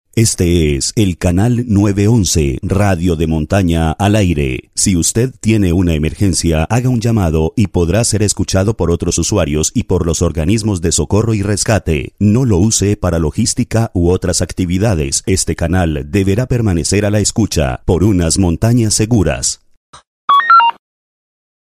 audio-baliza-9-11-radio-de-montana-esp.mp3